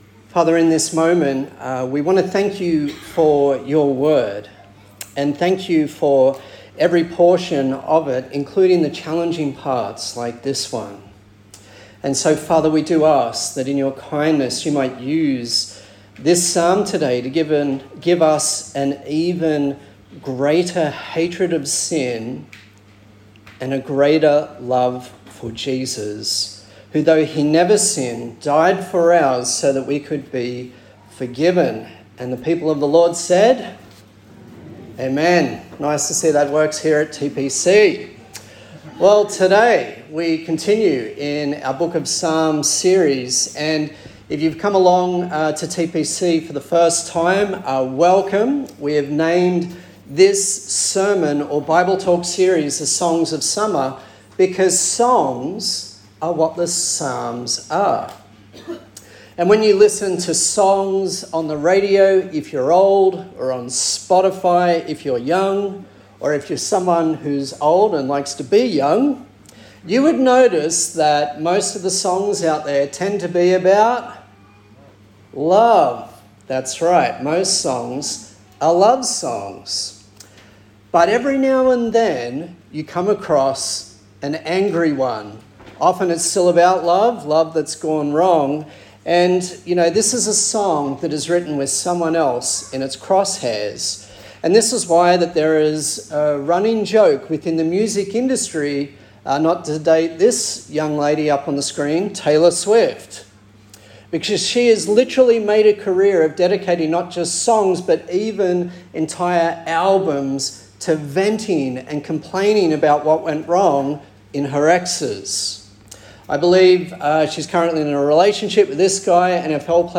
A sermon in the series 'Songs for Summer' featuring the book of Psalms.
Psalm 137 Service Type: Sunday Service A sermon in the series 'Songs for Summer' featuring the book of Psalms.